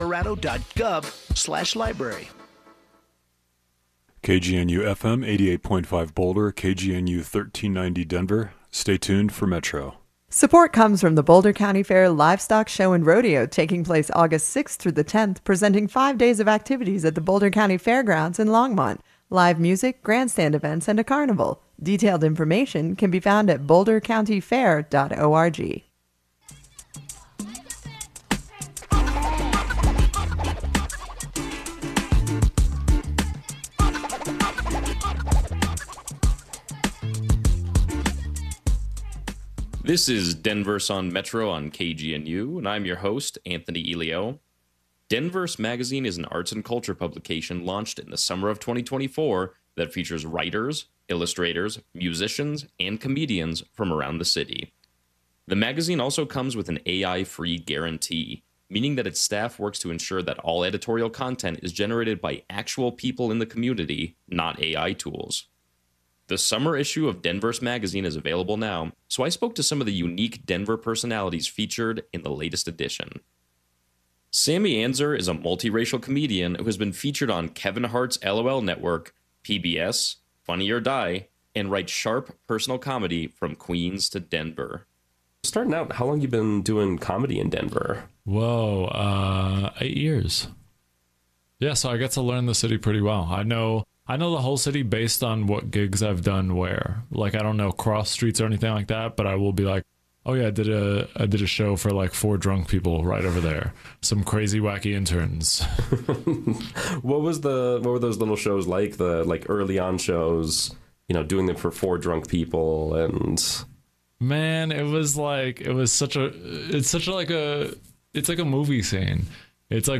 A conversation between radio host